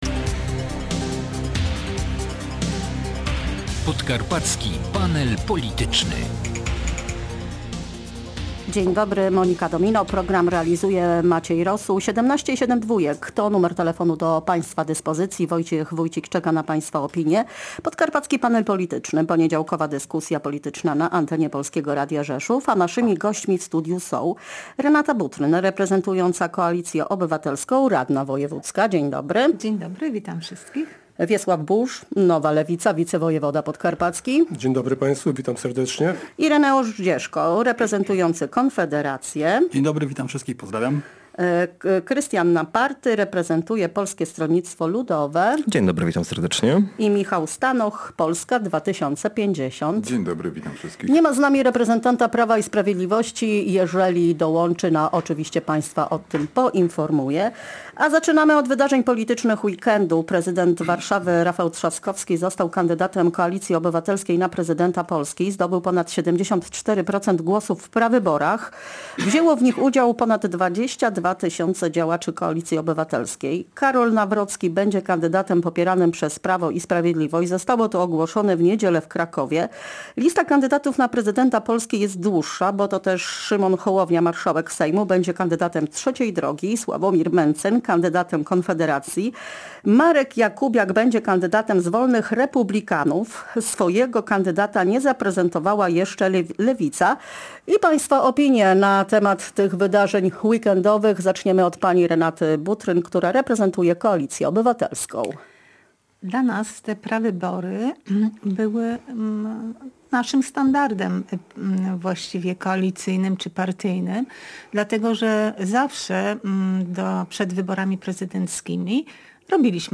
Podkarpaccy politycy zachwalali na naszej antenie kandydatów swoich ugrupowań w zbliżających się wyborach prezydenckich.